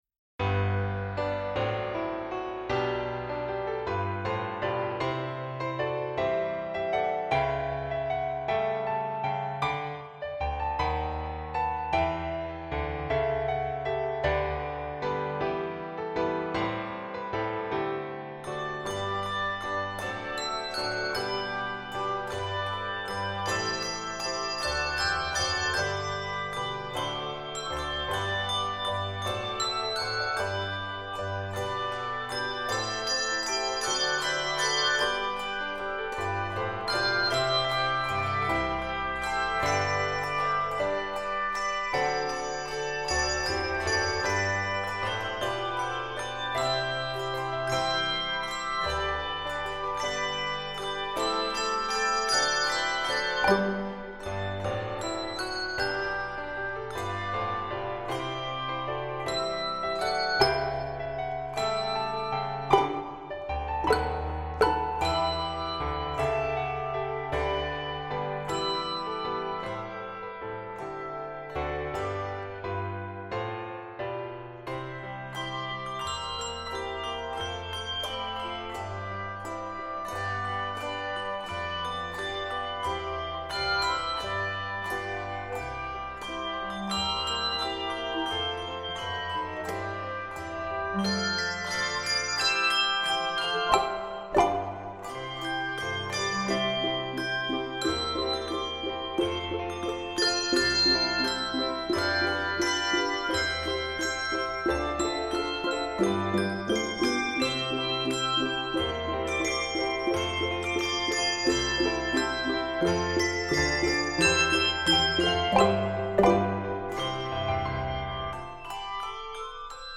spirited arrangement
Keys of G Major and C Major.